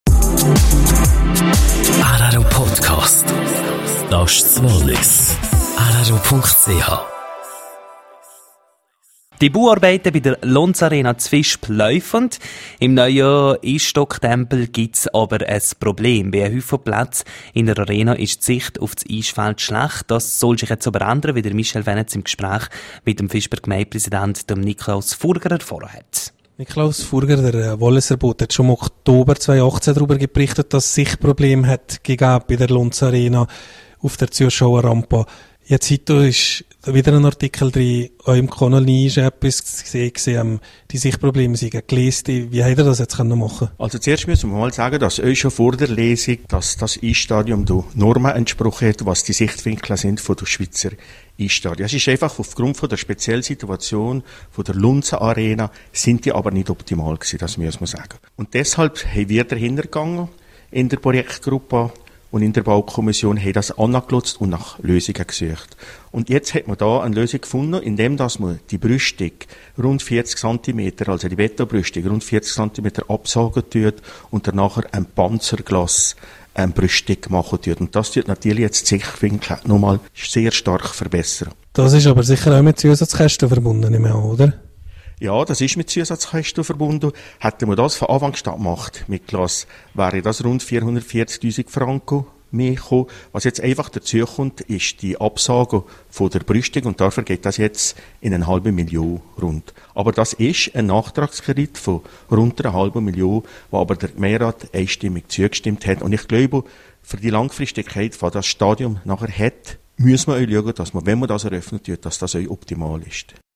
Nachtragskredit für die Lonza Arena in Visp: Gemeindepräsident Niklaus Furger im Interview.